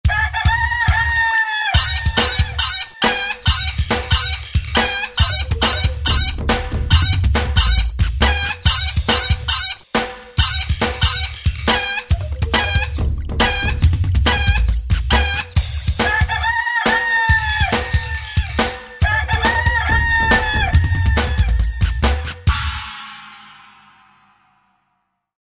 Categoría Graciosos